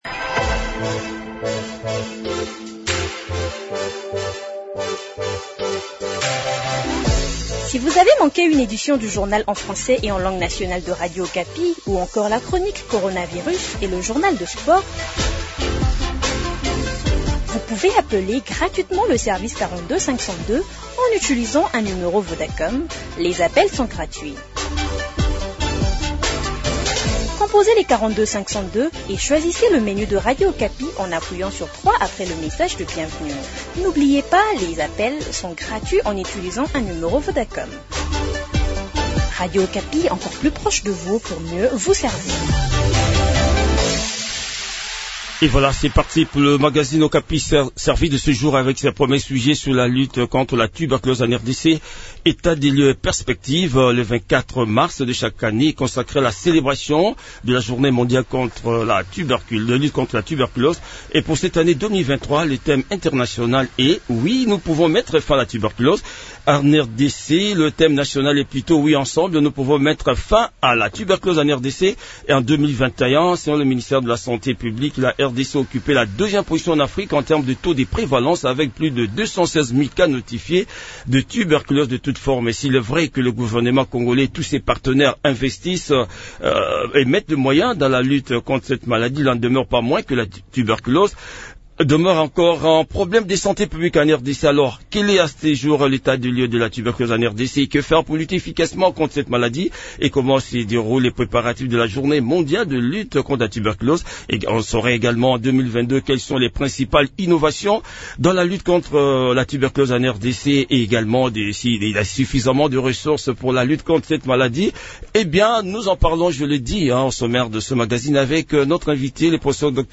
Les réponses à toutes ces questions dans cet entretien